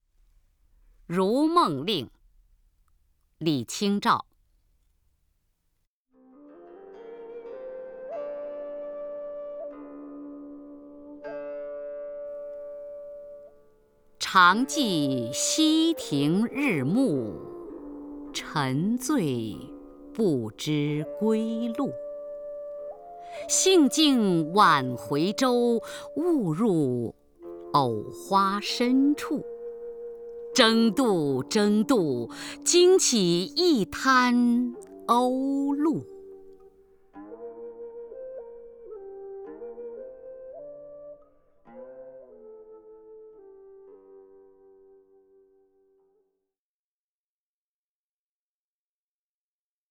首页 视听 名家朗诵欣赏 雅坤
雅坤朗诵：《如梦令·常记溪亭日暮》(（南宋）李清照)